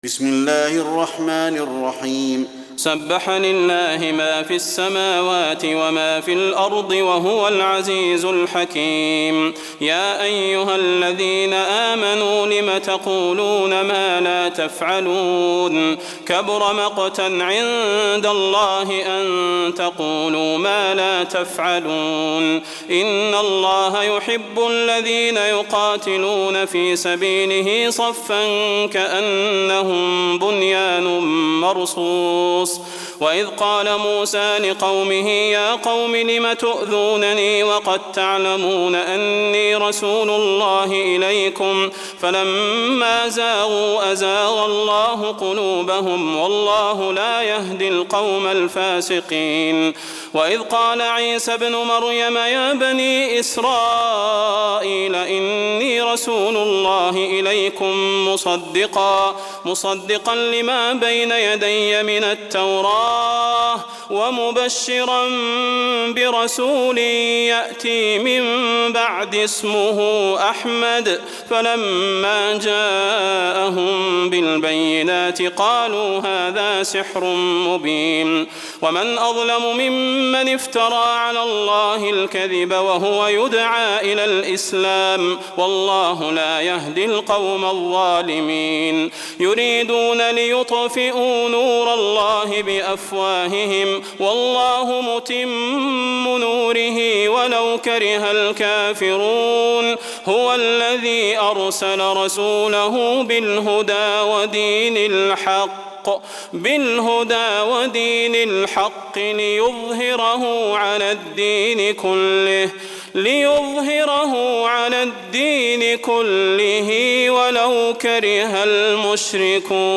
تراويح ليلة 27 رمضان 1423هـ من سورة الصف الى التغابن Taraweeh 27 st night Ramadan 1423 H from Surah As-Saff to At-Taghaabun > تراويح الحرم النبوي عام 1423 🕌 > التراويح - تلاوات الحرمين